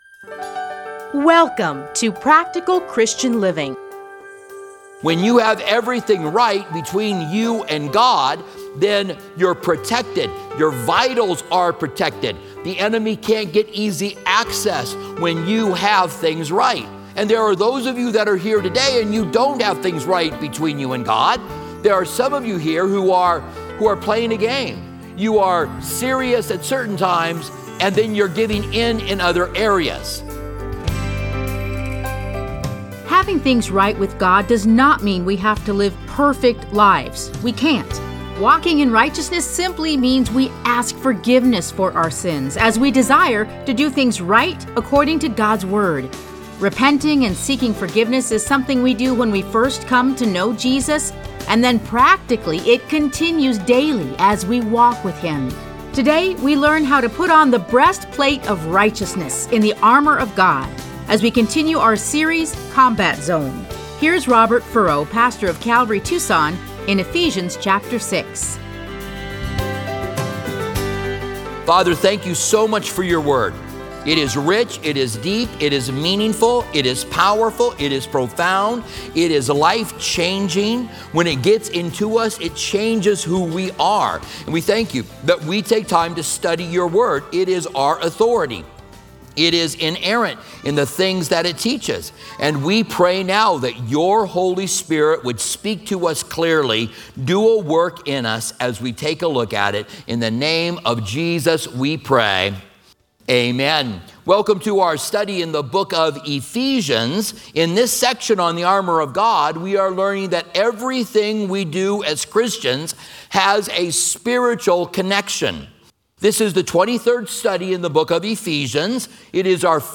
Listen to a teaching from Ephesians 6:14.